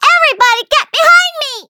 Taily-Vox_Skill2_a.wav